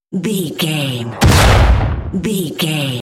Dramatic hit explosion
Sound Effects
heavy
intense
dark
aggressive
hits